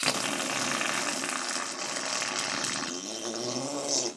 Tags: fart fart noises